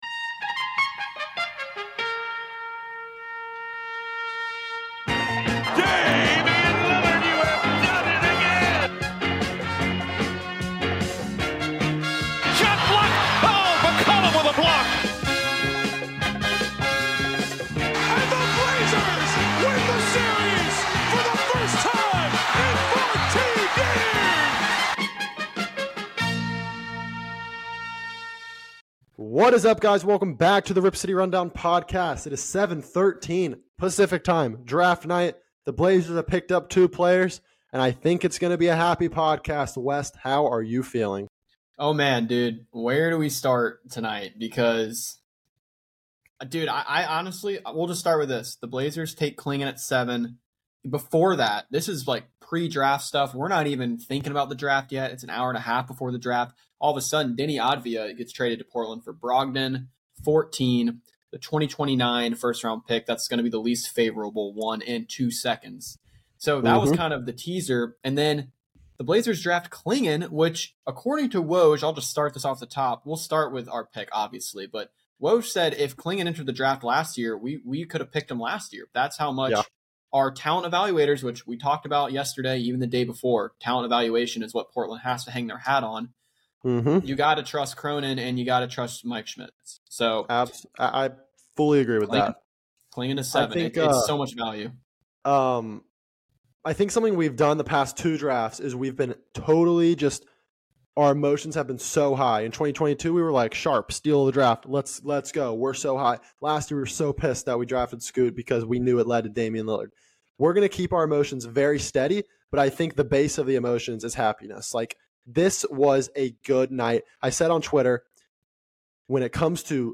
Our initial reaction to draft night is LIVE.